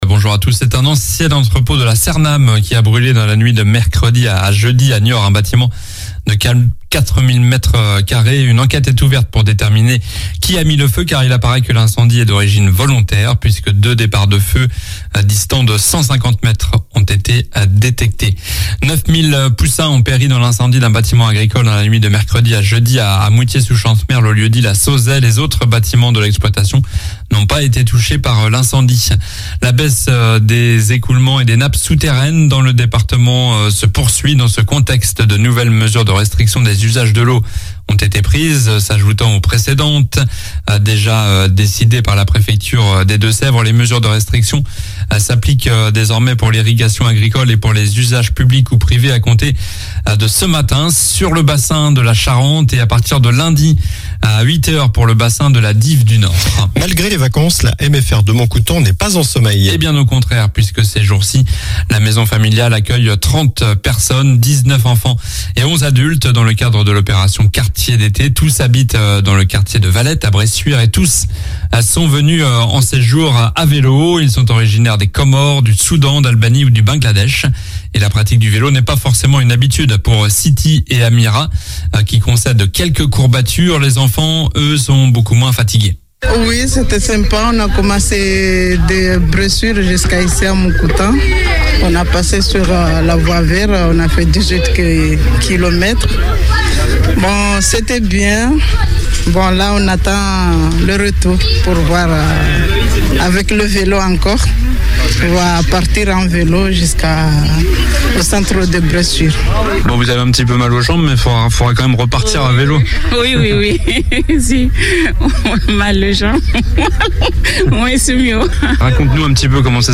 Journal du vendredi 29 juillet (matin)